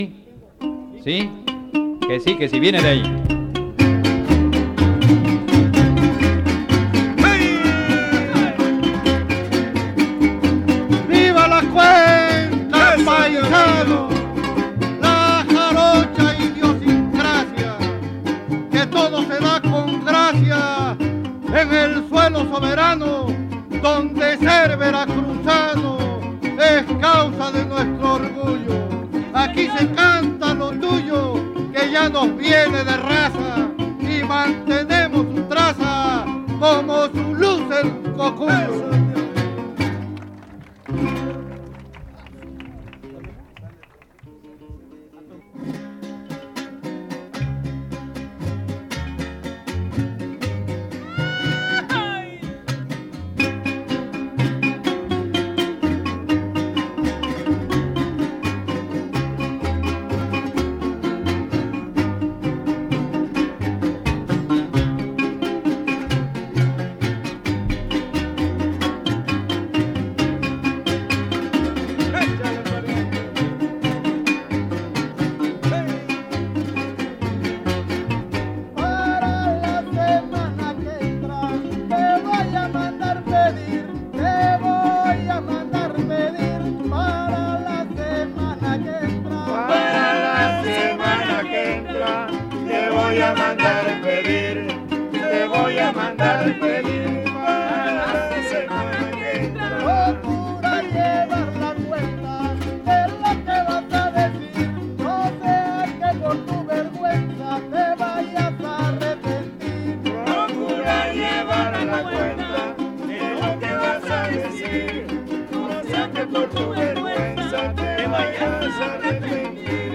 • Siquisirí (Grupo musical)
Noveno Encuentro de jaraneros